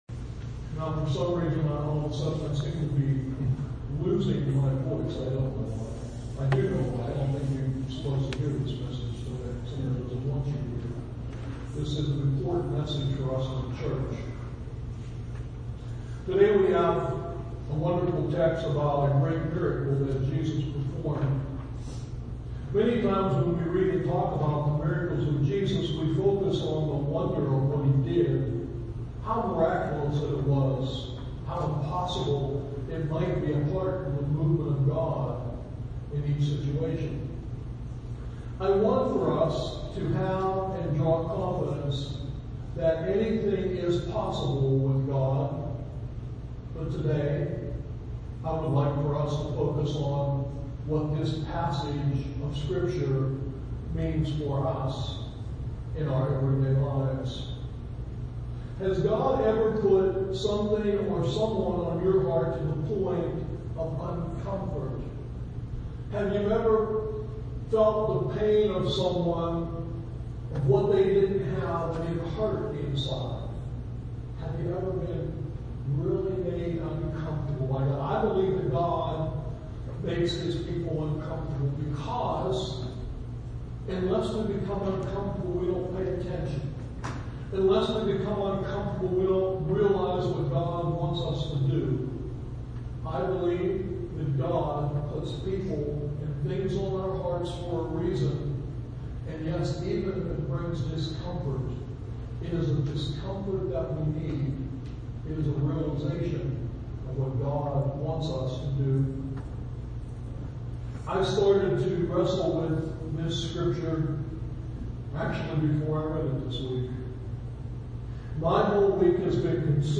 SERMON TEXT: Matthew 14:13-21